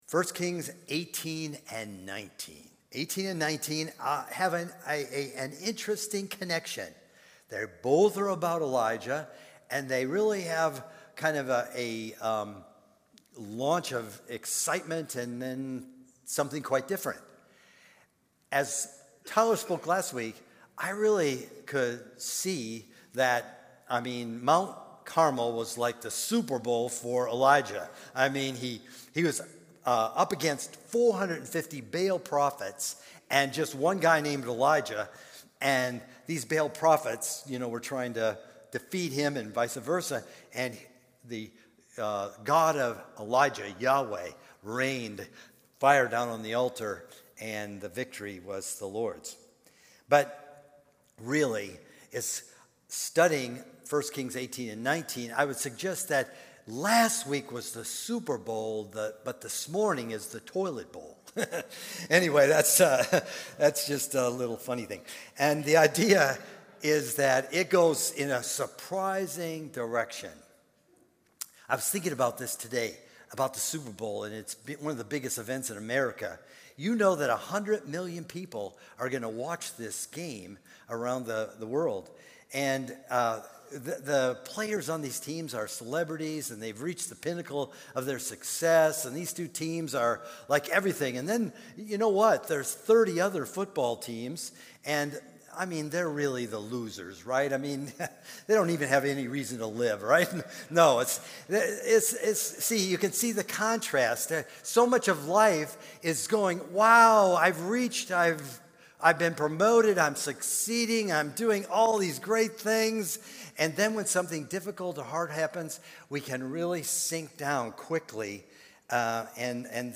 Sermon audio from February 11